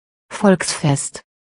A Volksfest (pronounced [ˈfɔlks.fɛst]